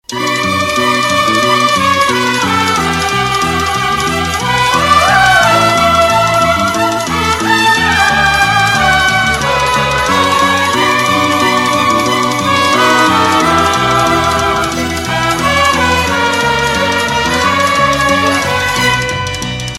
Рингтоны Без Слов
Поп Рингтоны